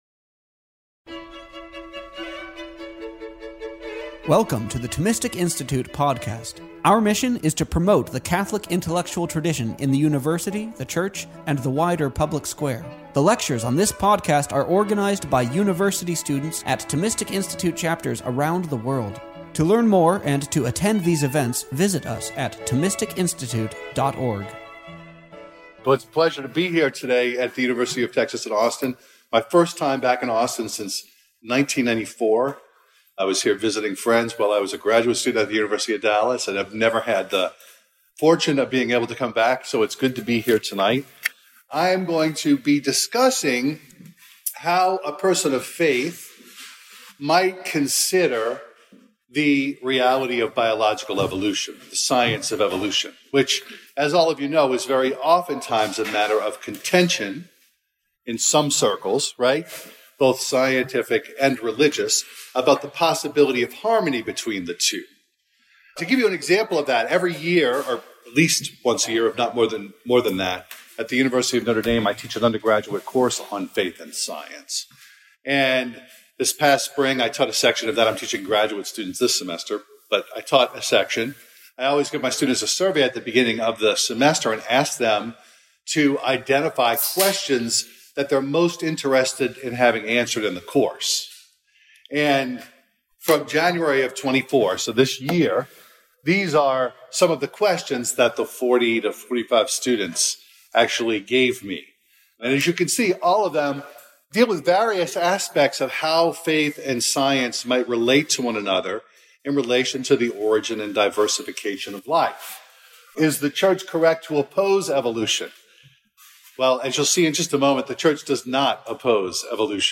The thought of St. Thomas Aquinas, the Universal Doctor of the Church, is our touchstone. The Thomistic Institute Podcast features the lectures and talks from our conferences, campus chapters events, intellectual retreats, livestream events, and much more.